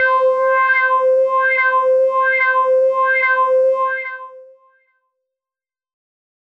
Organesq Pad C5.wav